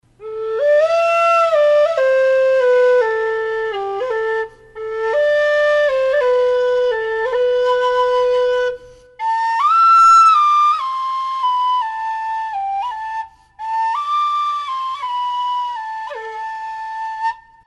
Альт G (металл)
Альт G (металл) Тональность: G
Альтовая модель вистла. Имеет хорошую отзывчивость и устойчивое звучание, как в низком диапазоне, так и в верхнем. Вистл изготовлен из алюминия, свистковая часть выполнена из пластика.